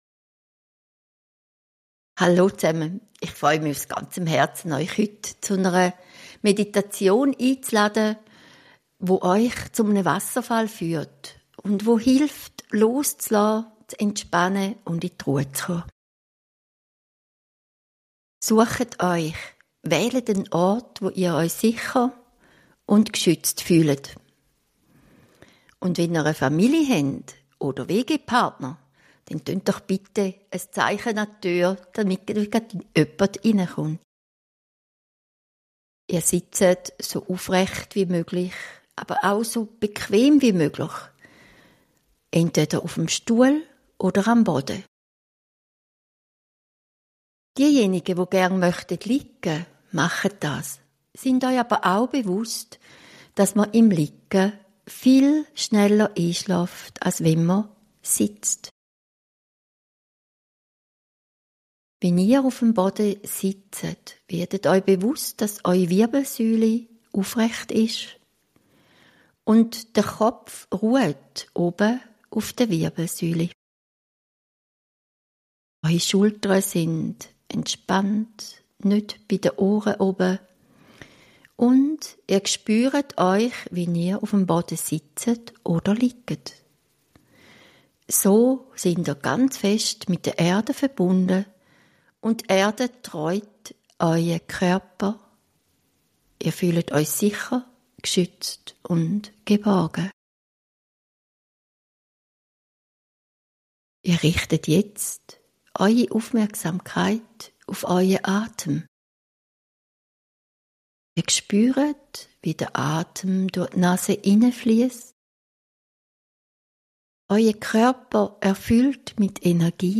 Bonus - Geführte Meditation: Reinigung und Entspannung am Wasserfall ~ Spirit of Life Podcast